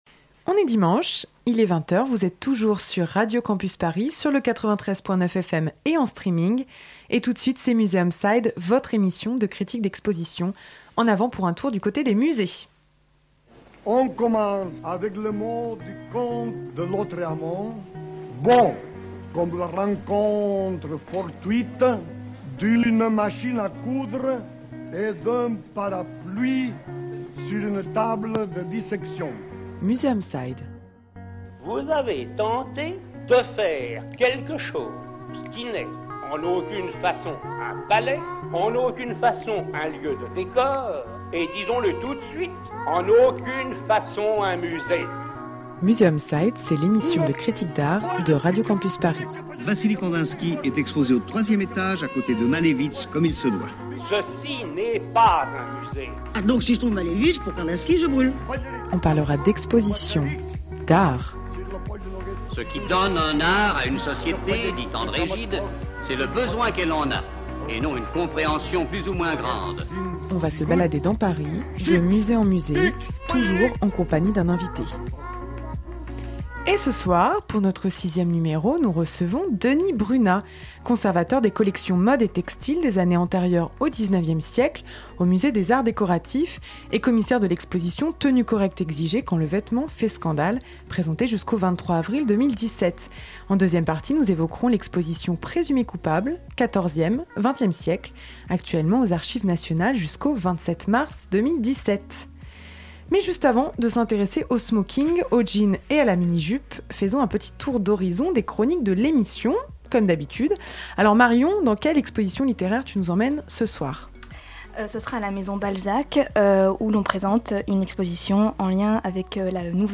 Le principe chaque mois : deux expositions à l’affiche dans de grands musées parisiens sur lesquelles nous débattons avec à chaque fois un invité spécialiste qui nous donne des clés de compréhension.